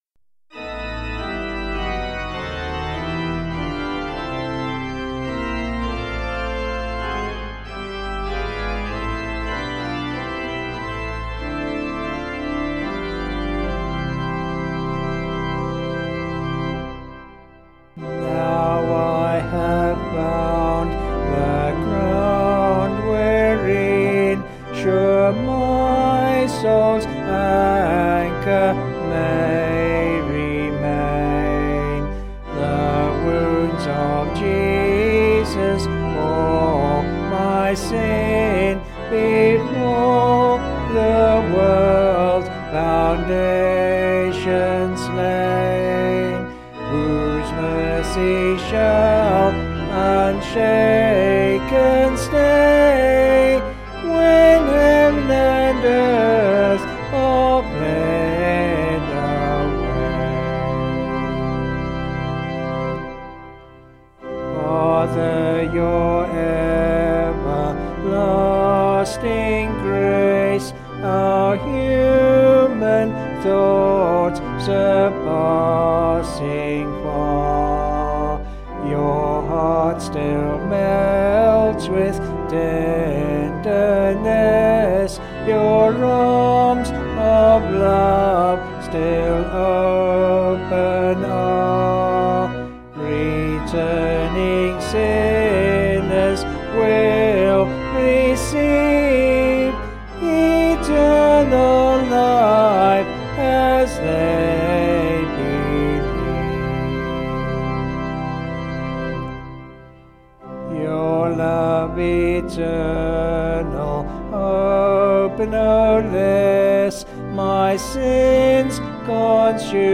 Vocals and Organ   266.5kb Sung Lyrics